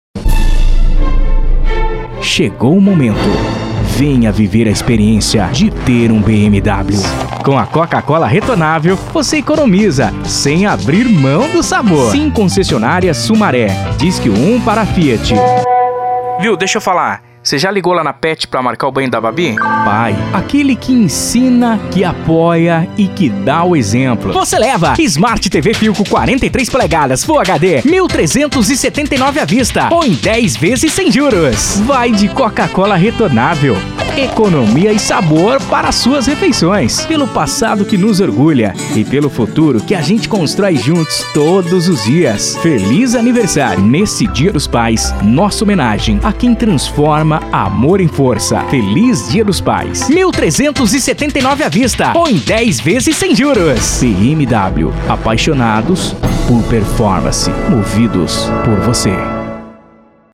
Animada